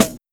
Track 02 - Snare OS 02.wav